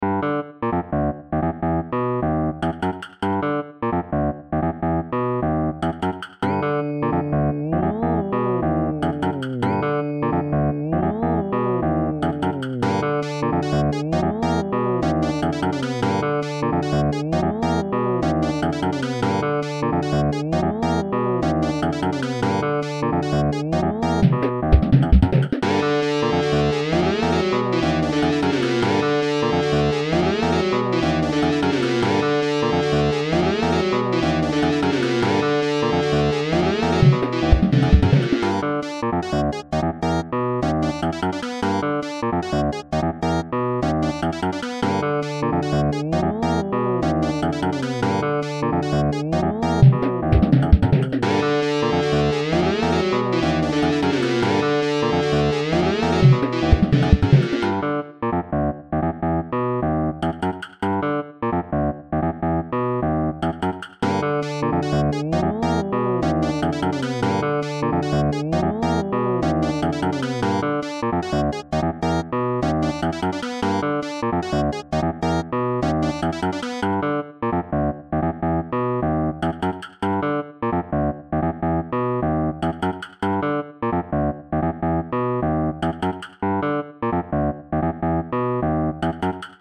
Nice guitar line, though